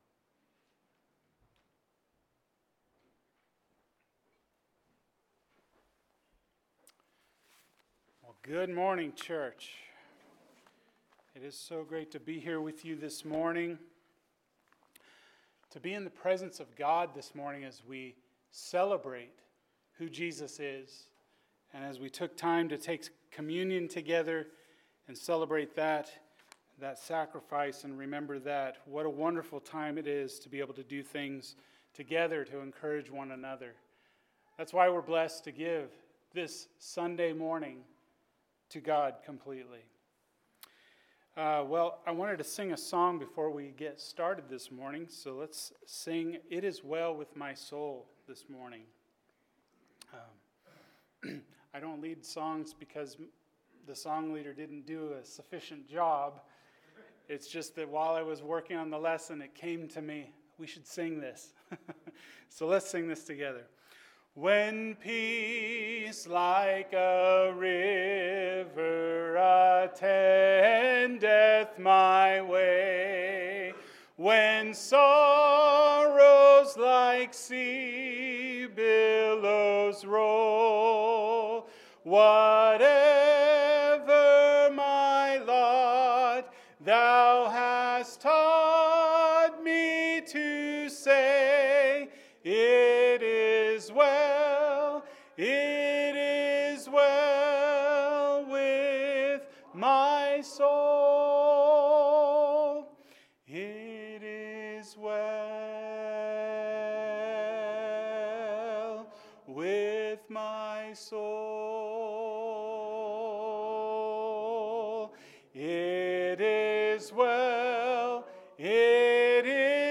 The Cross Gives Us Endurance – Hebrews 12:1-2 – Sermon